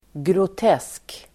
Uttal: [grot'es:k]